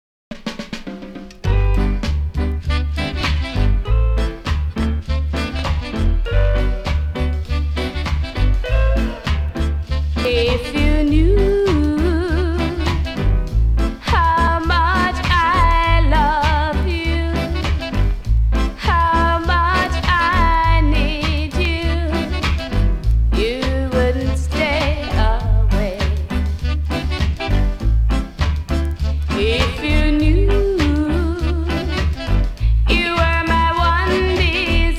Жанр: Реггетон
# Reggae